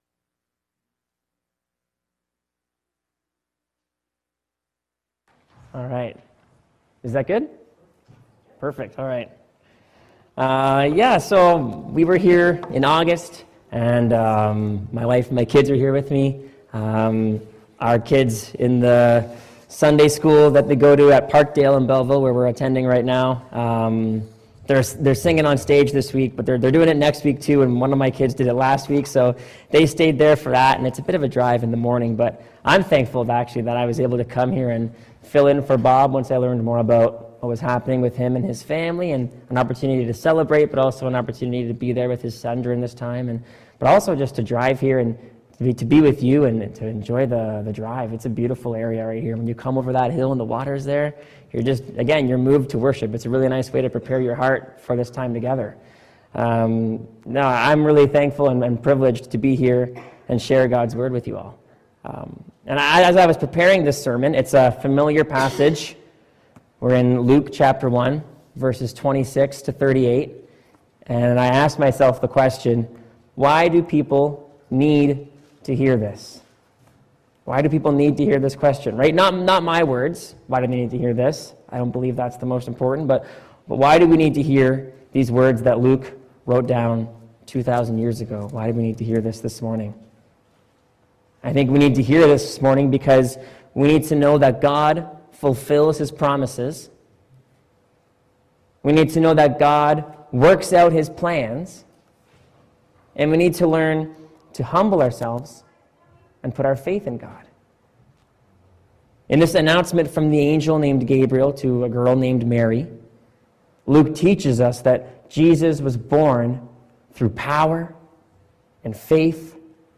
Service Type: Sermon
Dec-8-2024-sermon.mp3